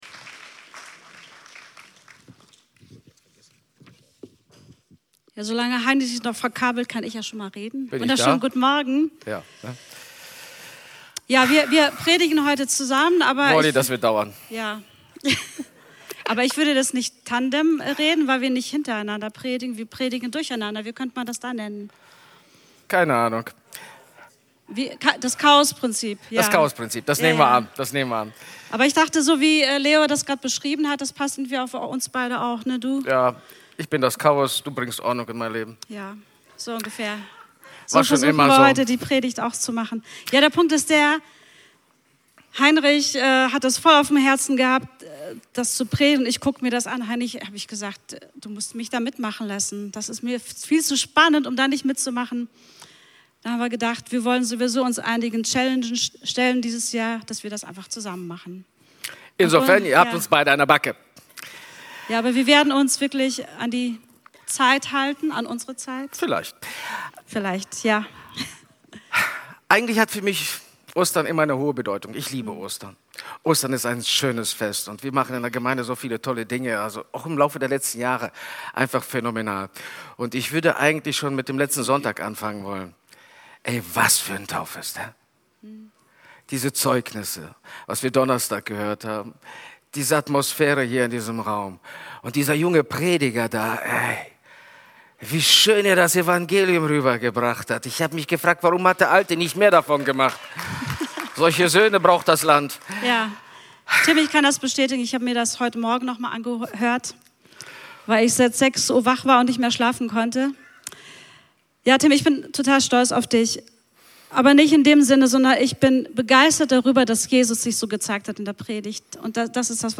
Podcast unserer Predigten